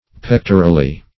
pectorally - definition of pectorally - synonyms, pronunciation, spelling from Free Dictionary Search Result for " pectorally" : The Collaborative International Dictionary of English v.0.48: Pectorally \Pec"to*ral*ly\, adv. As connected with the breast.
pectorally.mp3